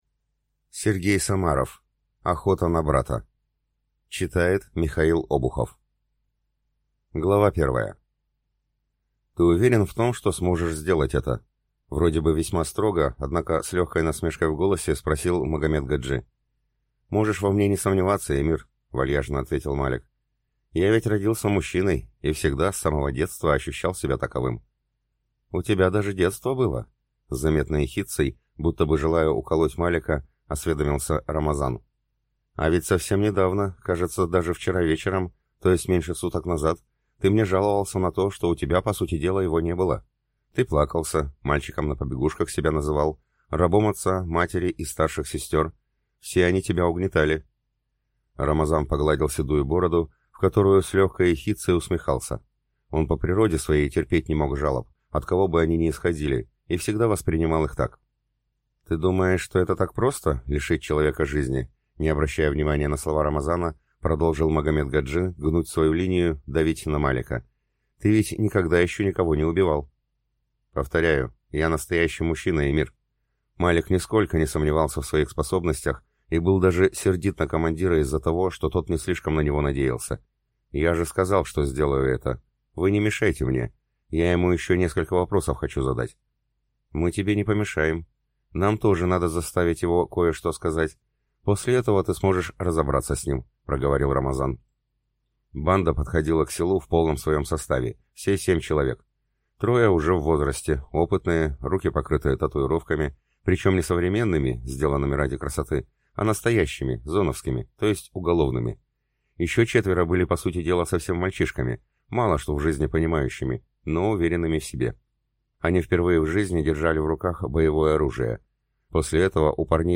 Аудиокнига Охота на брата | Библиотека аудиокниг